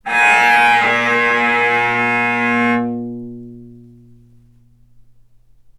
healing-soundscapes/Sound Banks/HSS_OP_Pack/Strings/cello/sul-ponticello/vc_sp-A#2-ff.AIF at a9e67f78423e021ad120367b292ef116f2e4de49
vc_sp-A#2-ff.AIF